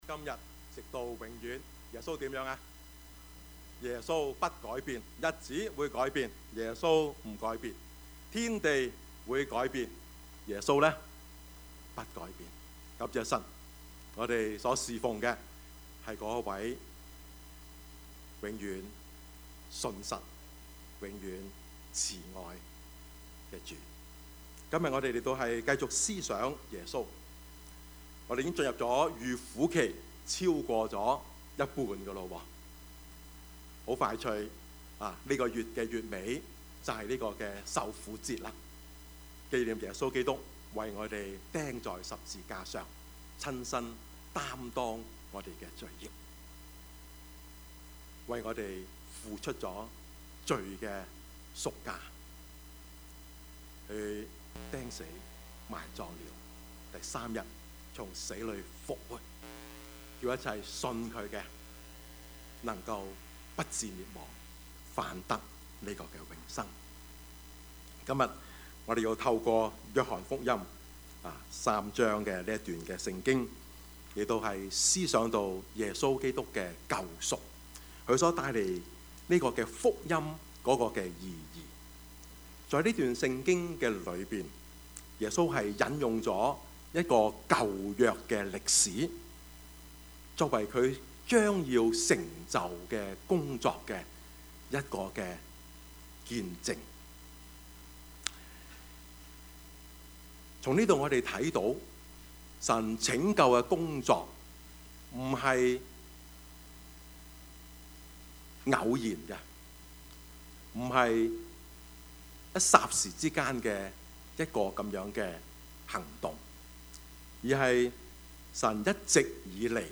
Passage: 約 翰 福 音 3:14-21 Service Type: 主日崇拜
」 Topics: 主日證道 « 陳獨秀(二) 痛而不苦 »